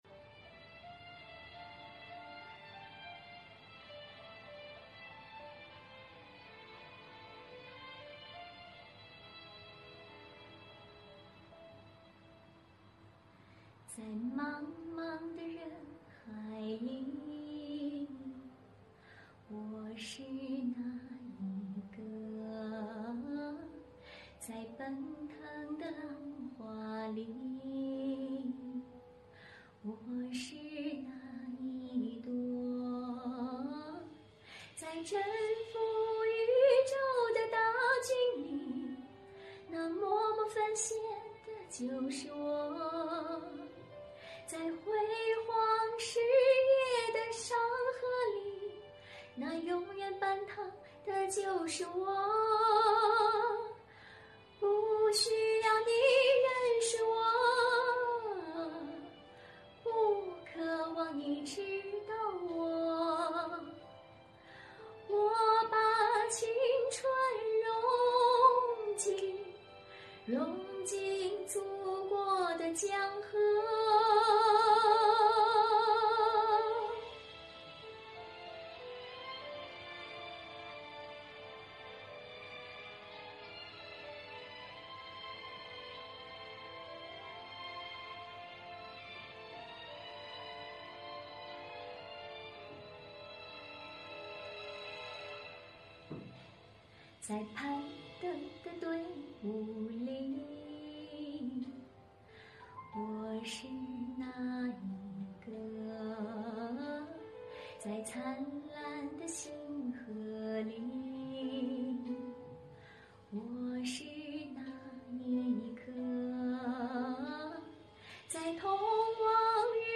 女声独唱